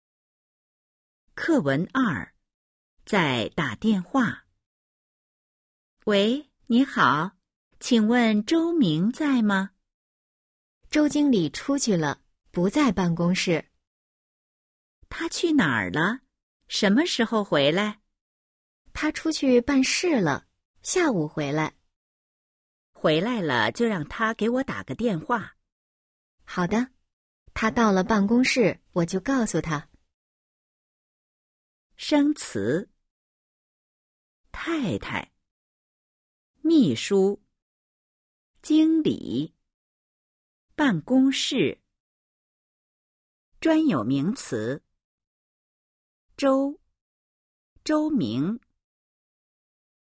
Bài hội thoại 2: 🔊 在打电话 – Đang gọi điện thoại  💿 02-02